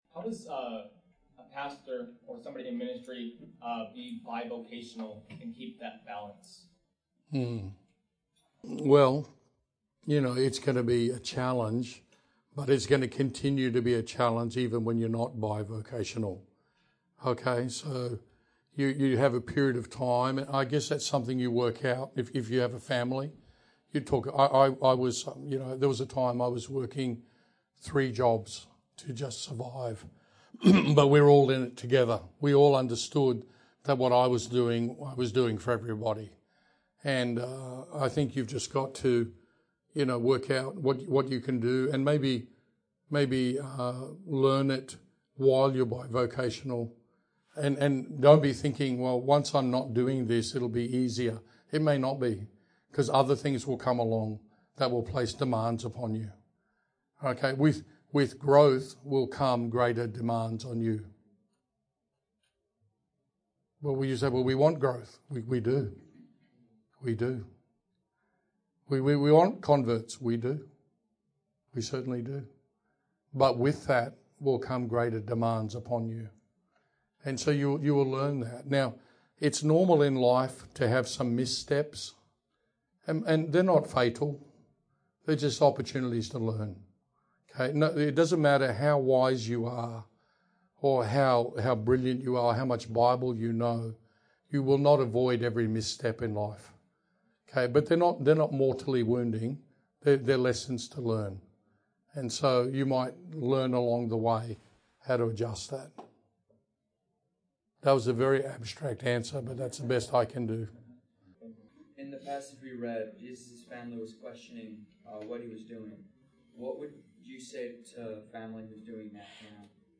The questions are a little hard to hear in the recording, so we have listed the subject of each question below.